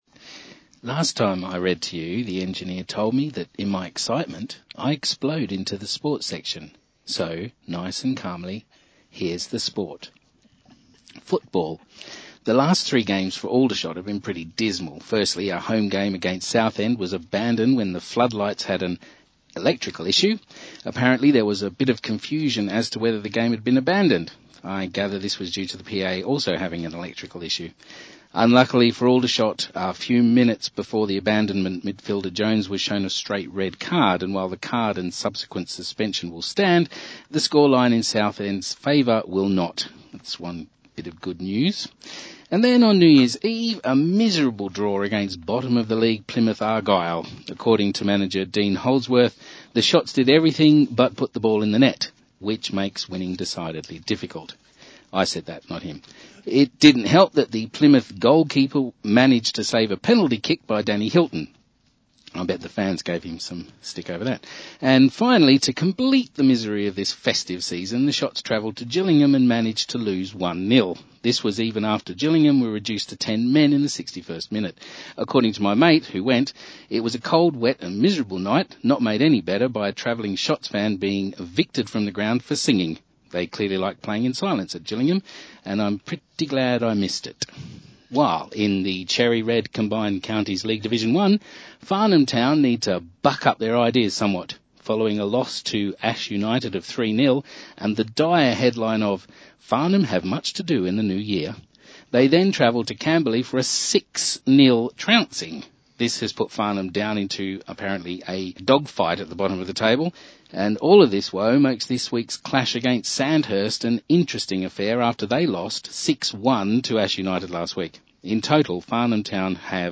It’s important to note that the other readers were all trying not to laugh.
Very good but had to keep hearing it as breaking up but after 4 times heard it all. yes I remember the picture on Castle Street.